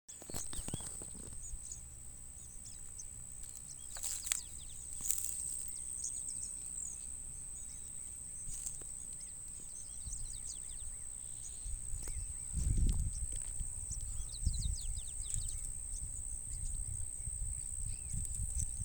Caminheiro-zumbidor (Anthus chii)
Nome em Inglês: Yellowish Pipit
Detalhada localização: Dique Río Hondo
Condição: Selvagem
Certeza: Gravado Vocal